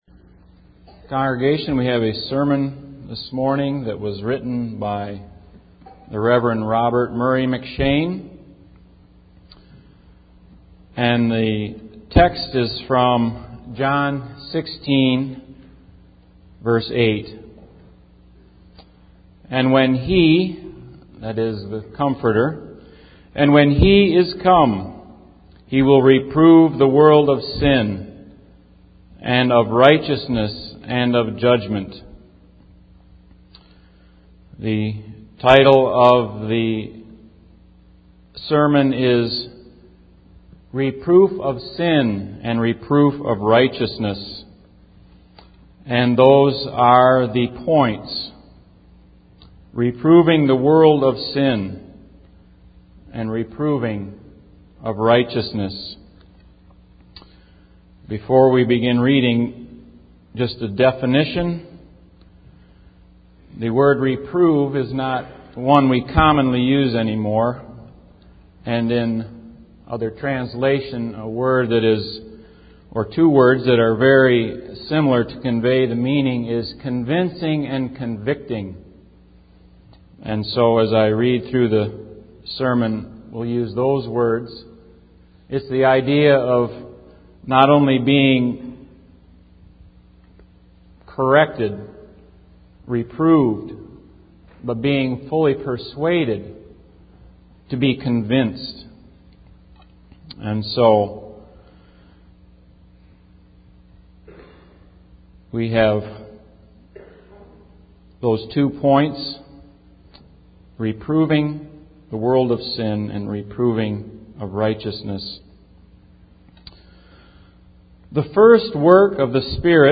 Convincing of Sin and Righteousness (Reading) by Robert Murray M'Cheyne | SermonIndex